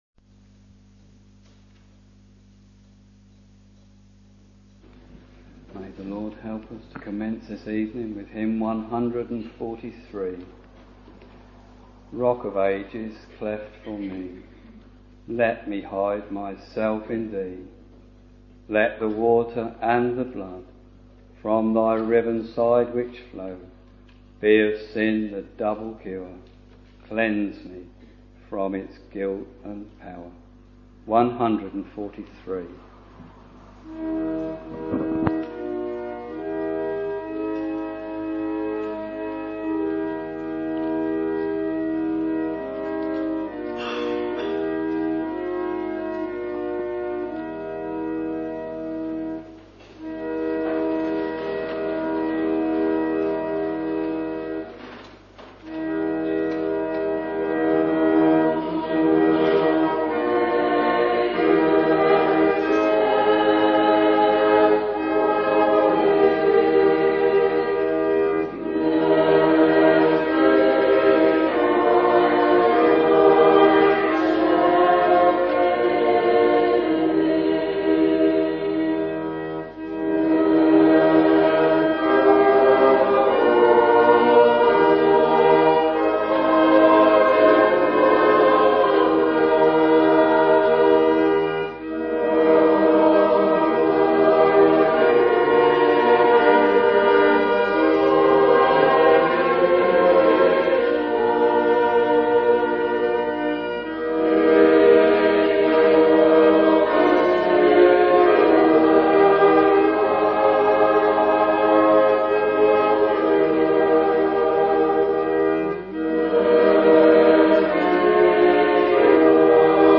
Anniversary Services — Evening Service